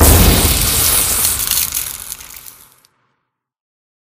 zombie